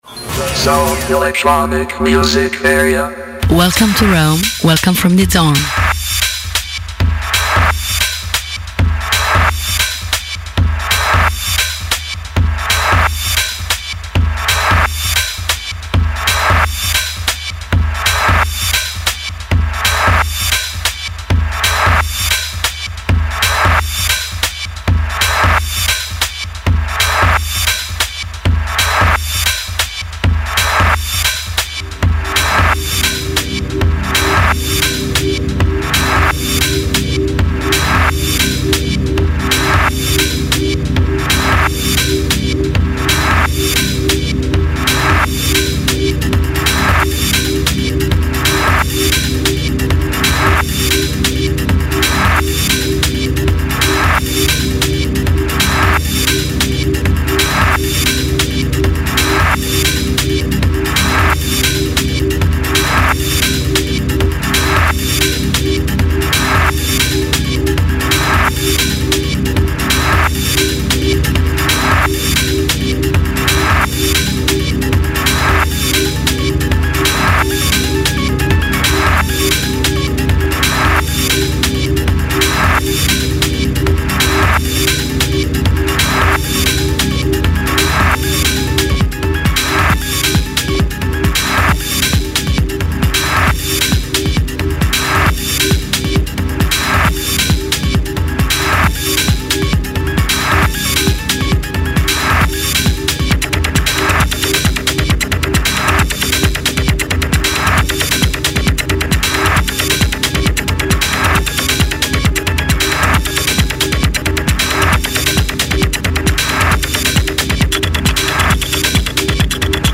Only one hour of Live show!